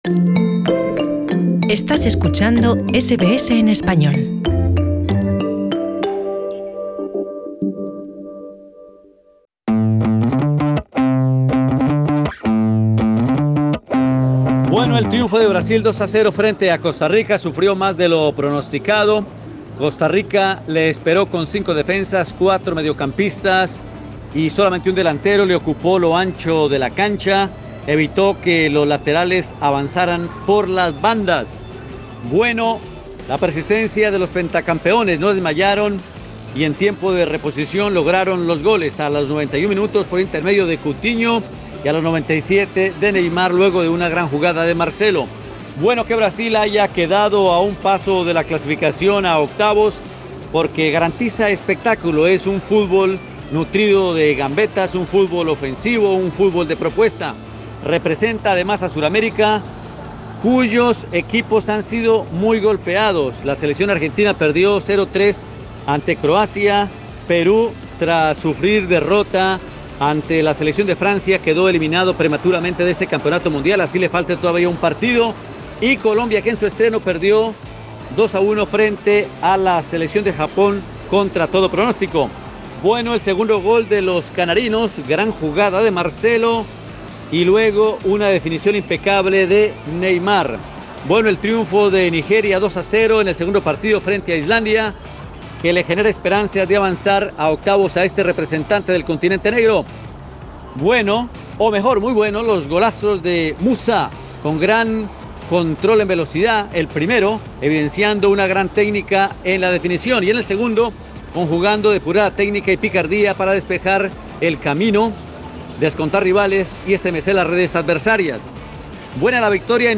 El comentarista deportivo
analiza desde Rusia los aspectos que hicieron lo bueno, lo malo y lo feo del día 8 en la Copa del Mundo.
Escucha el análisis arriba en nuestro podcast.